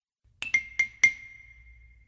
Звуки ксилофона
Удары палочками по ксилофону